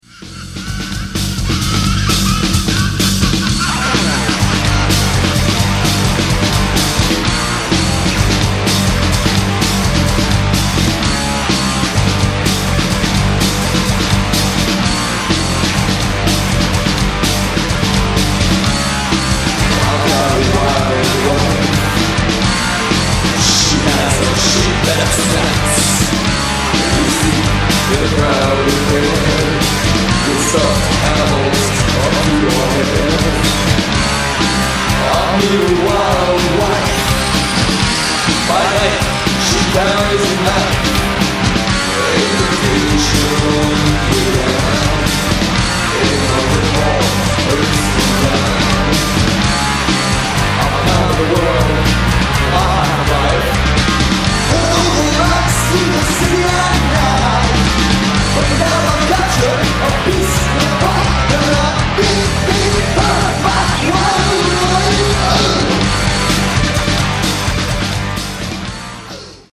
goth rock
original punk beginnings
melding their primal energy with goth-rock